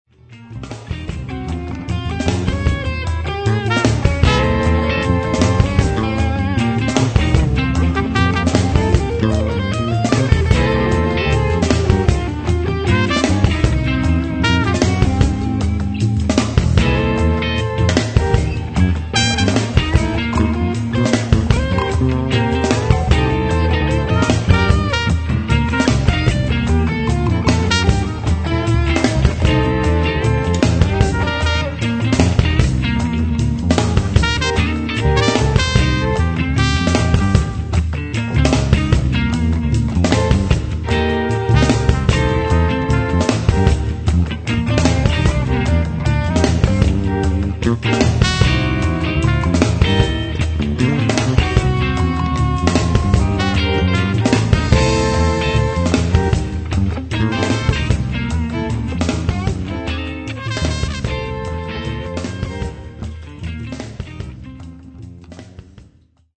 and bass, guitars, keyboard, sax and trumpet.